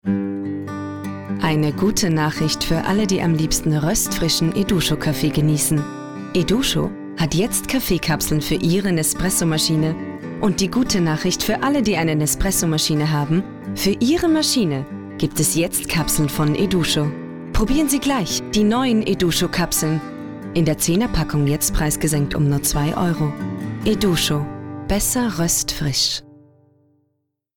Demo – Werbung – OFF